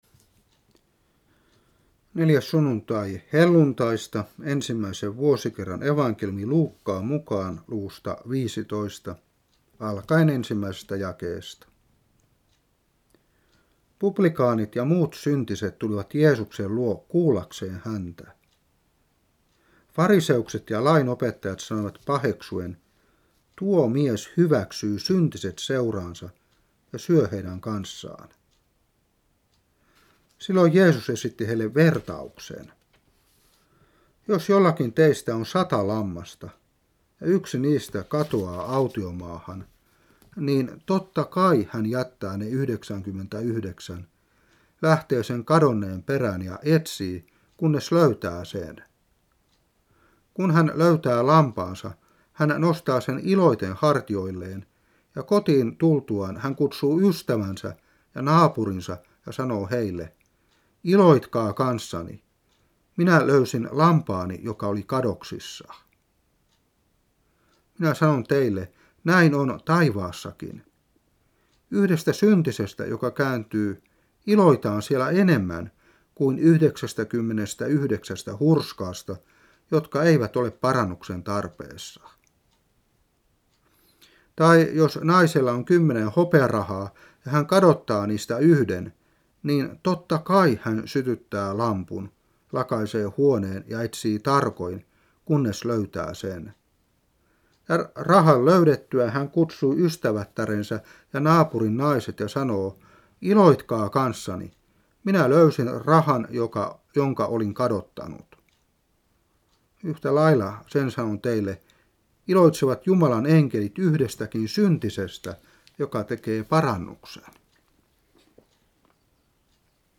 Saarna 1996-6.